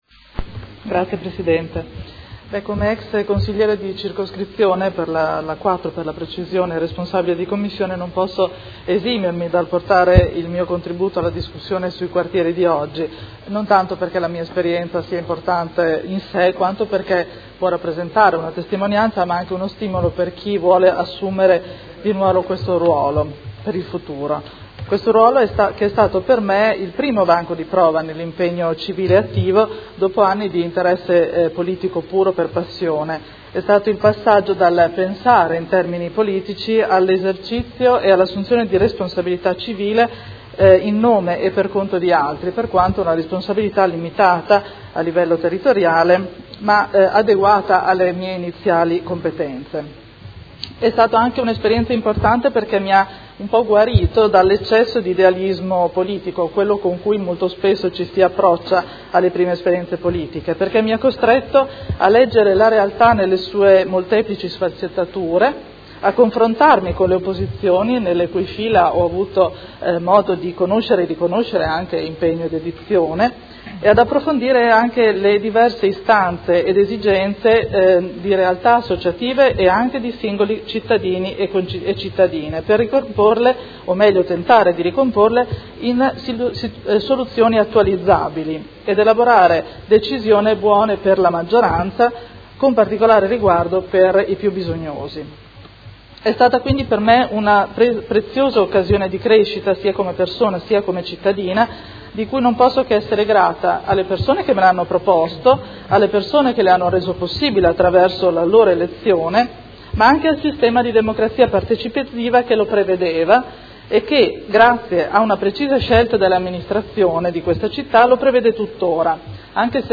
Chiara Pacchioni — Sito Audio Consiglio Comunale